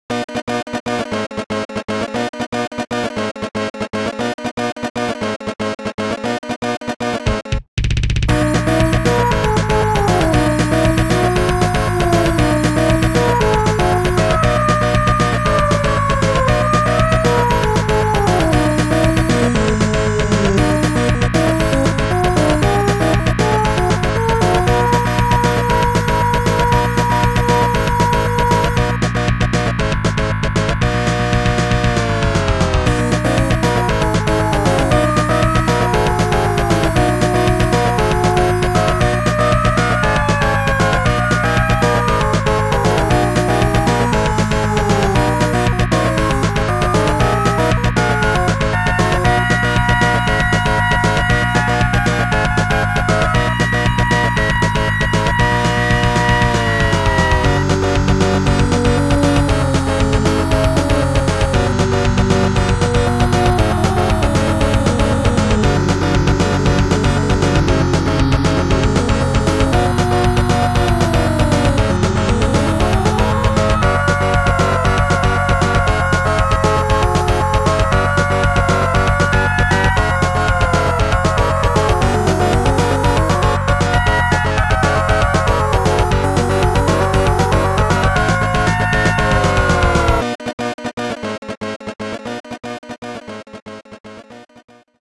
This file is an audio rip from a(n) SNES game.
An unused music track from the SNES game